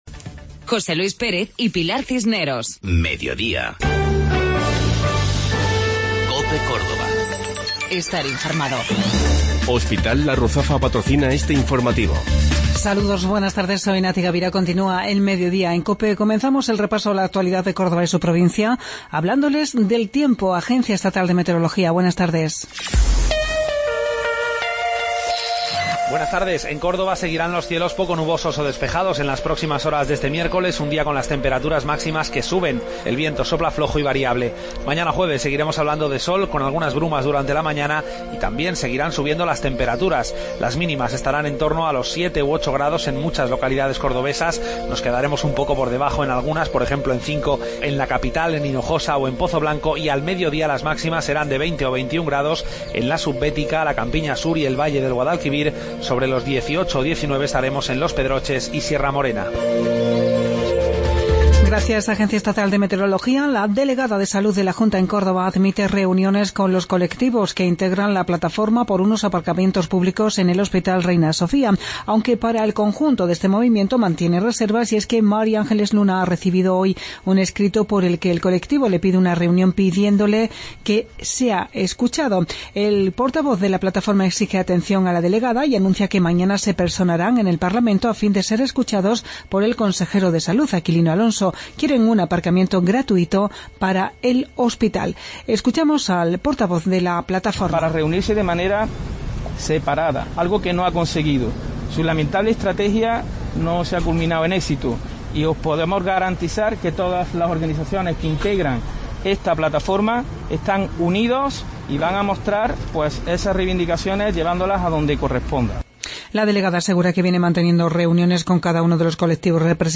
Mediodía en Cope. Informativo local 1 de Marzo 2017